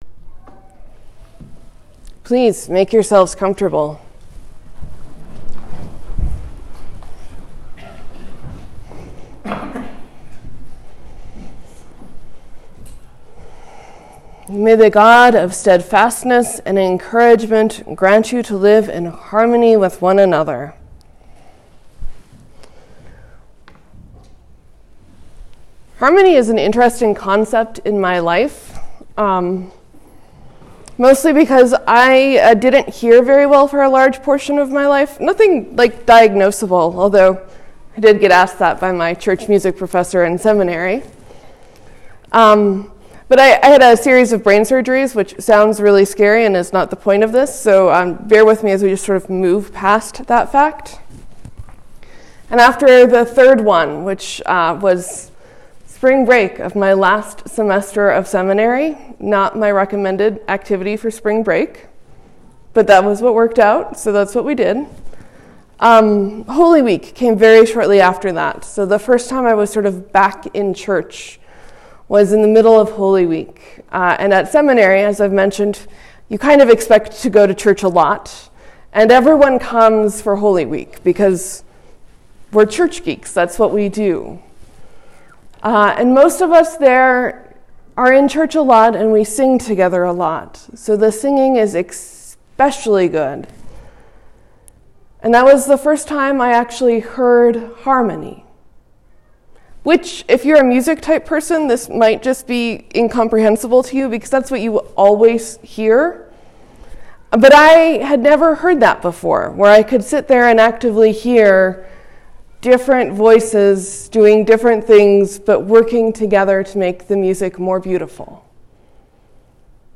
Advent, Sermon, , , , Leave a comment